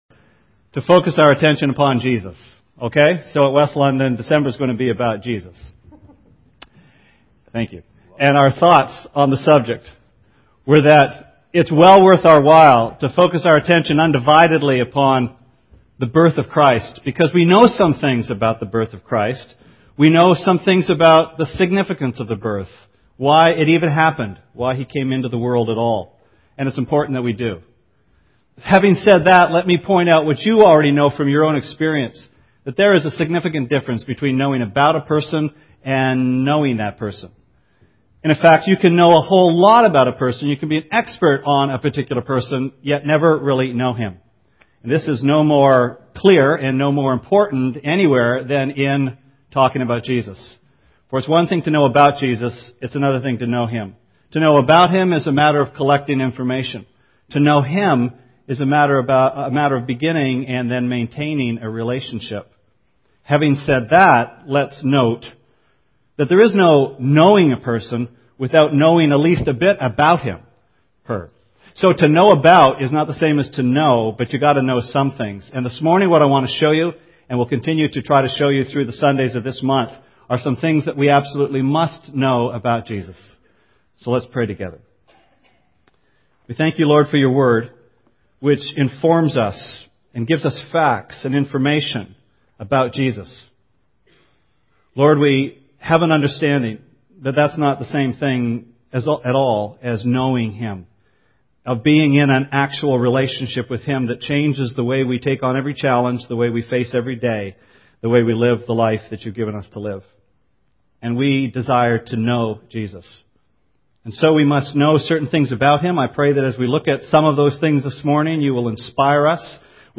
The first of four December sermons on the subject of Christ as the ultimate desire of the human heart. This morning we consider the words of Christ recorded in John 6:35.